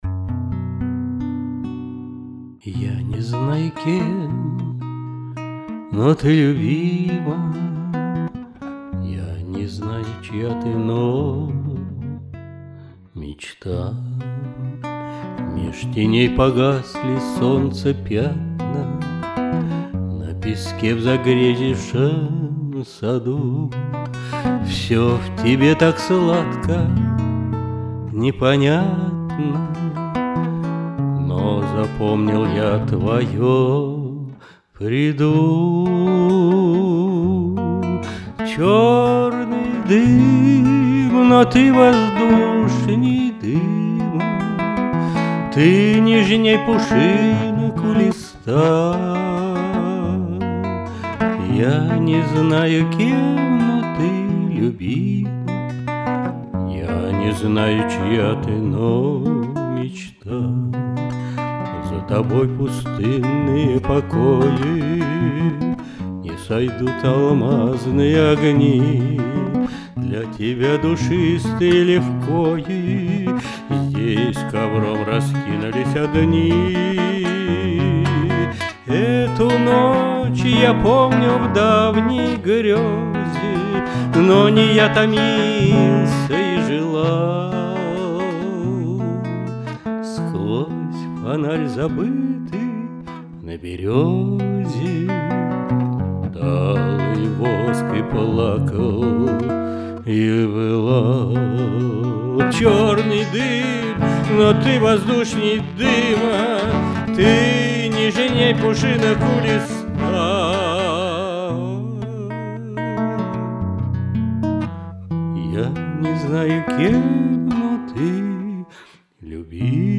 вокал, гитара
Записано в студии